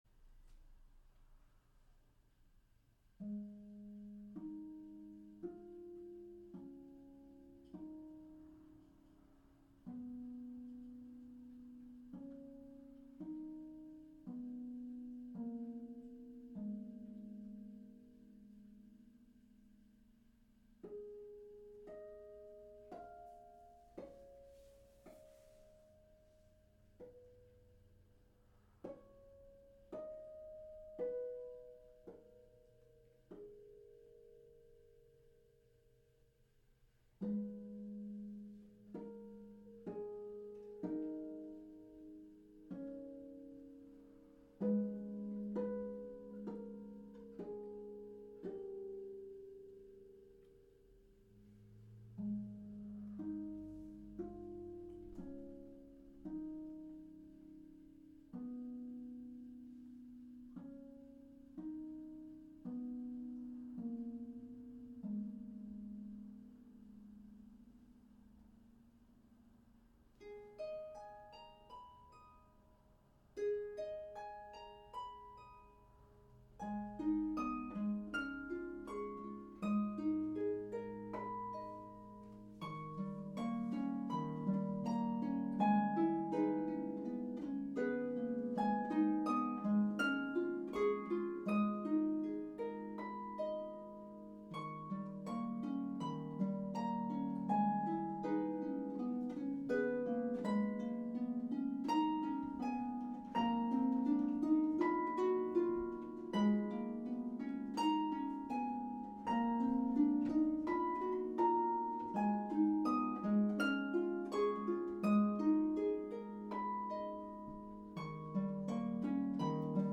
traditional French carol